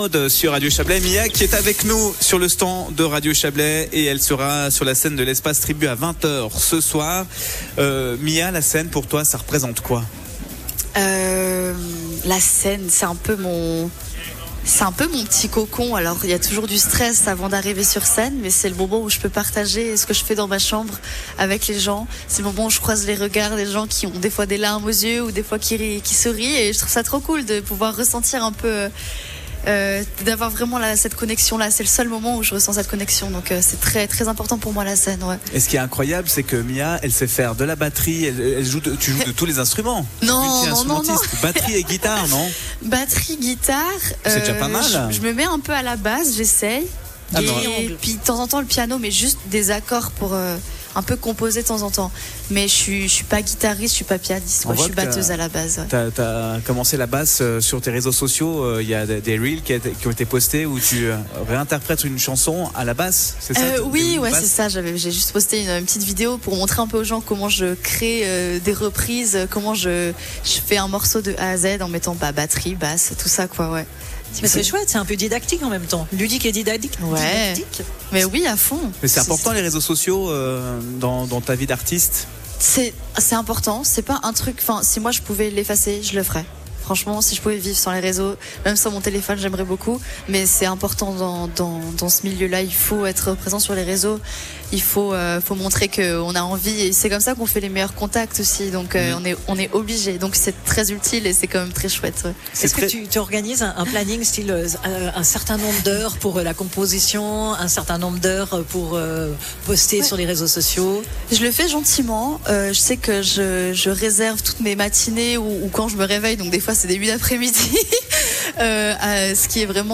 sur le stand de la radio avant son concert à l'espace Tribu